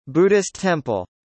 「Buddhist temple」の英語発音はこちら▼